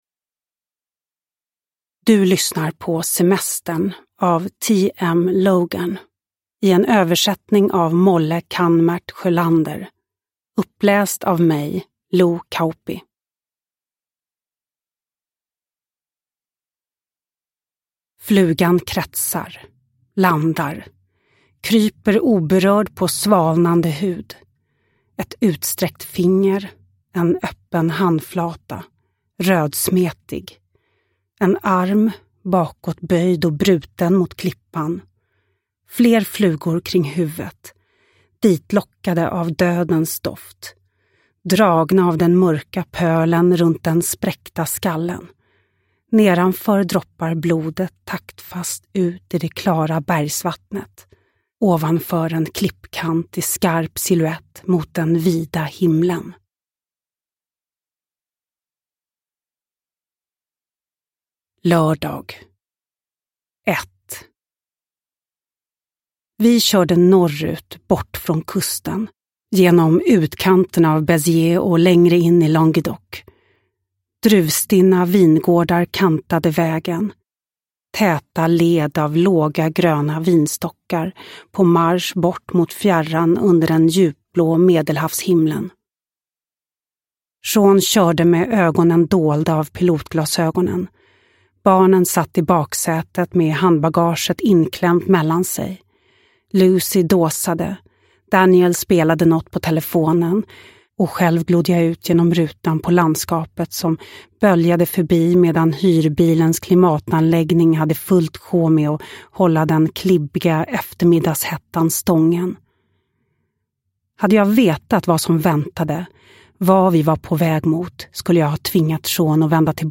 Semestern – Ljudbok – Laddas ner
Uppläsare: Lo Kauppi